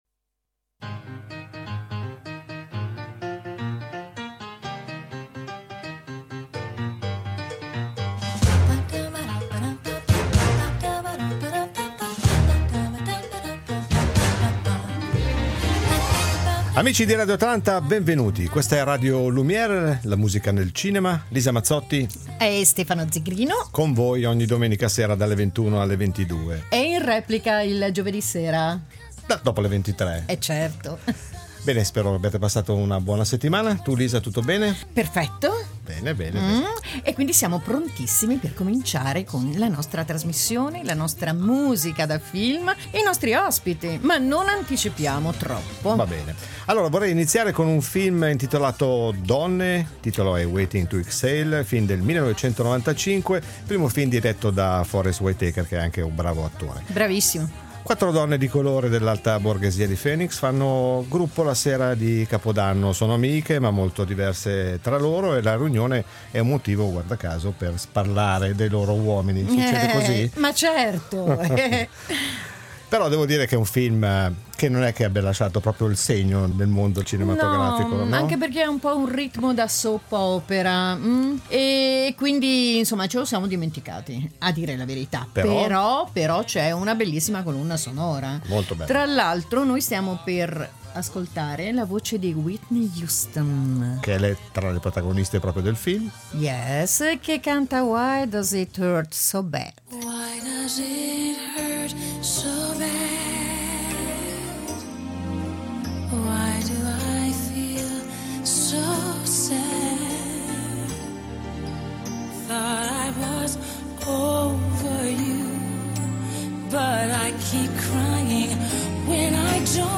Ospite di questa puntata in collegamento telefonico